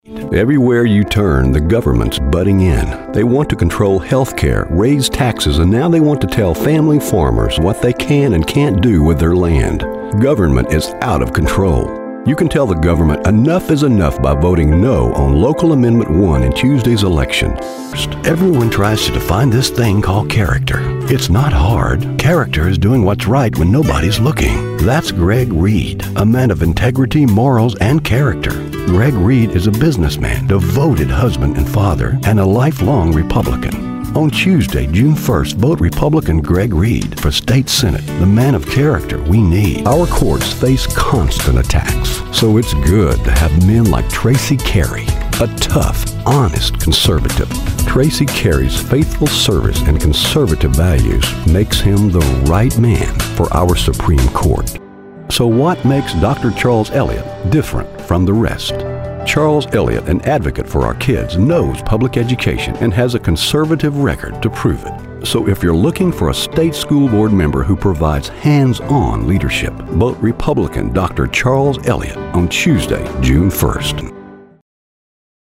Political Demo
Slight southern, Texas accent
Middle Aged